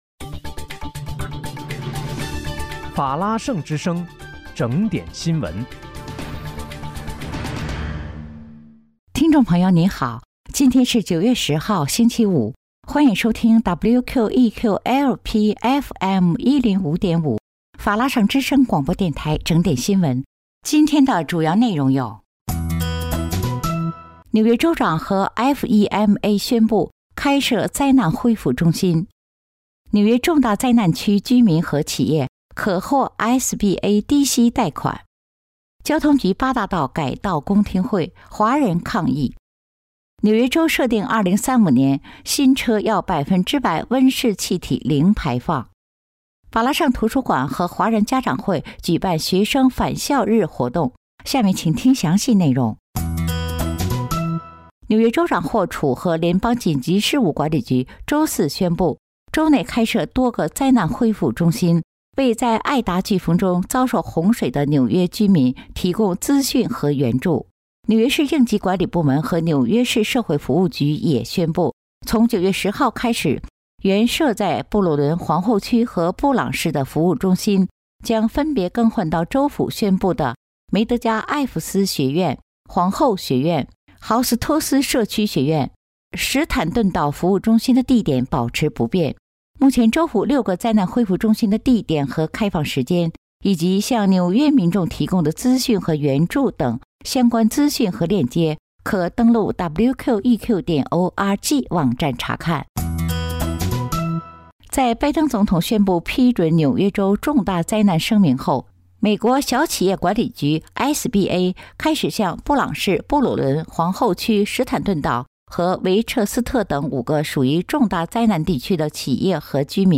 9月10日（星期五）纽约整点新闻